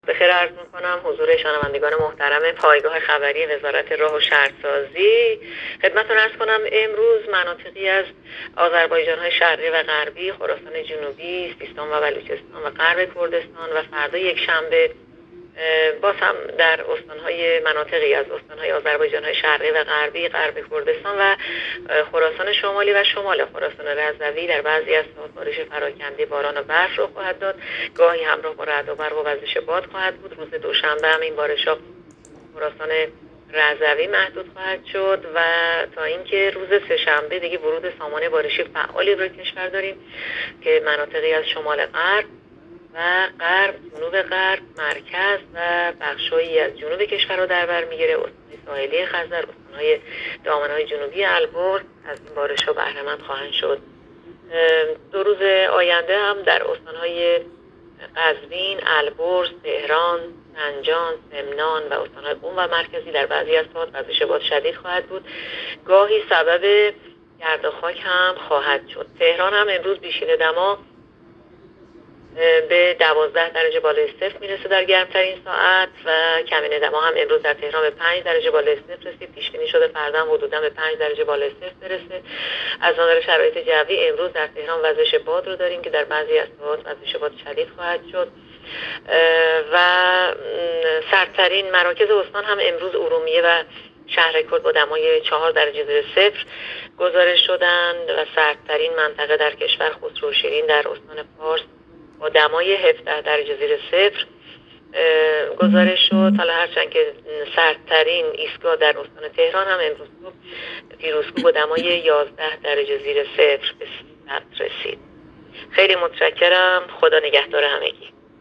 گزارش رادیو اینترنتی از آخرین وضعیت آب و هوای یازدهم بهمن؛